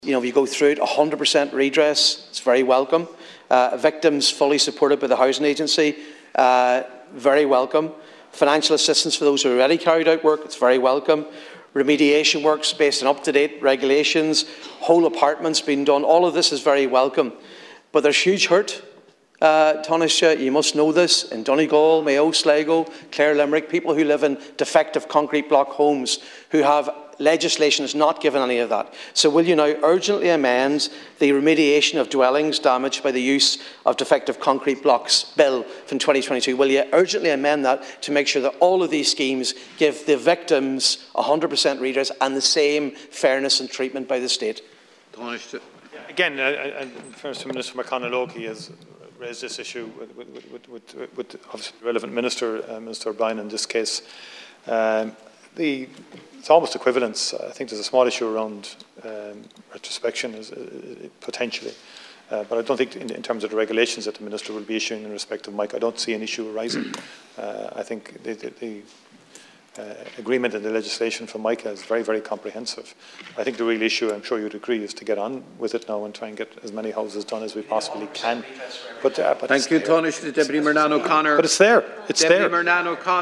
Dail told of ‘huge hurt’ in Donegal over defective apartments redress
He’s urging the government to amend the defective block legislation: